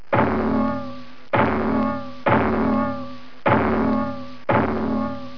دانلود صدای کارتون 42 از ساعد نیوز با لینک مستقیم و کیفیت بالا
جلوه های صوتی
برچسب: دانلود آهنگ های افکت صوتی اشیاء دانلود آلبوم صداهای کارتونی از افکت صوتی اشیاء